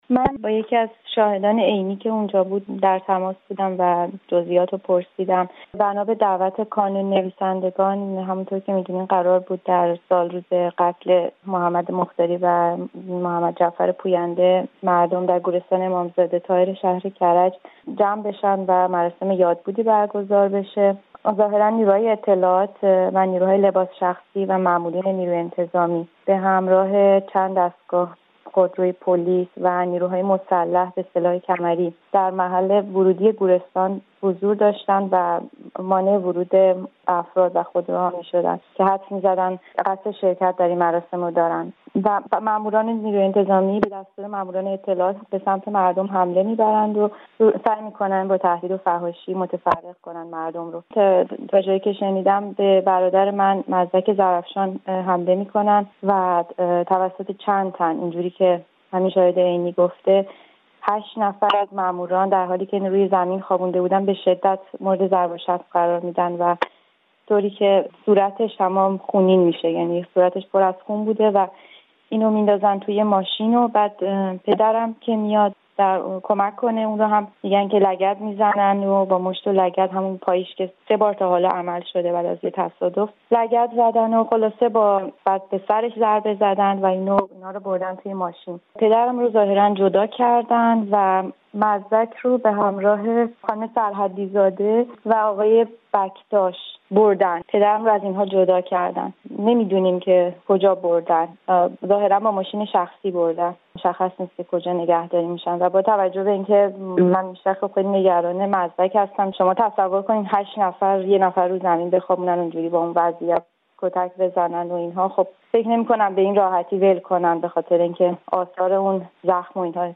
در گفت‌وگو با رادیو فردا